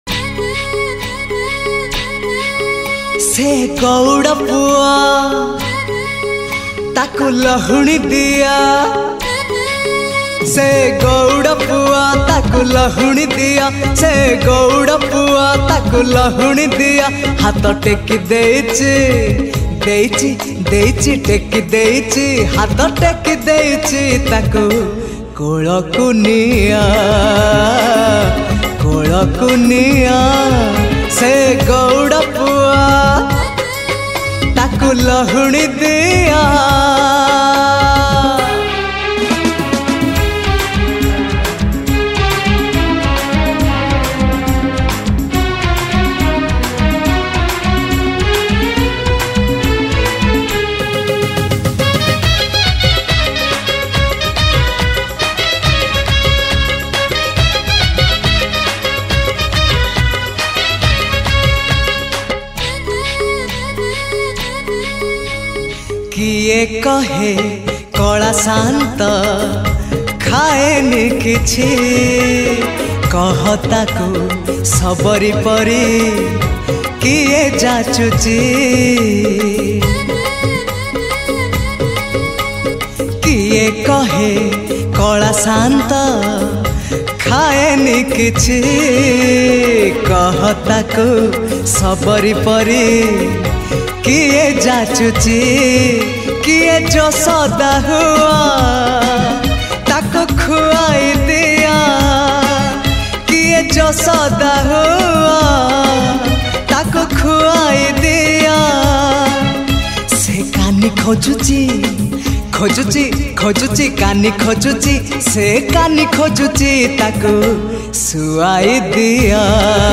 Odia Bhajan